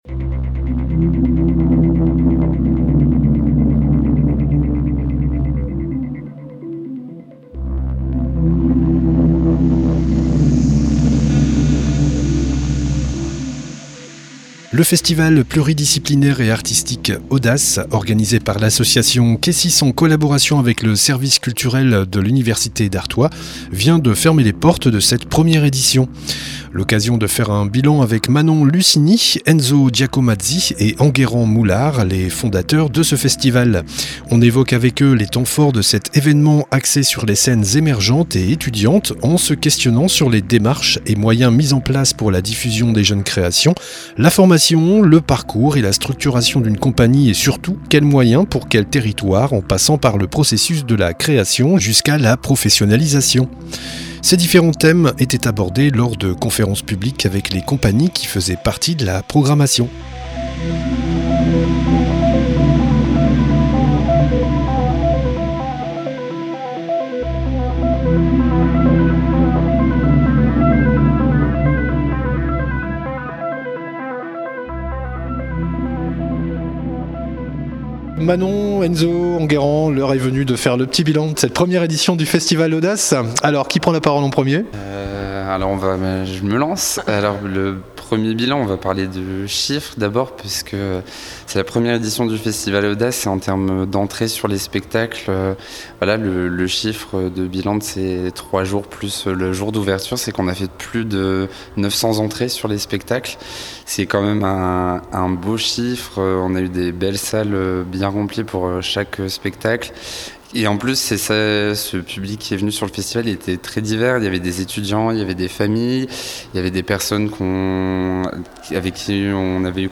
BILAN FESTIVAL AUDACE(S) REPORTAGES/ENTRETIENS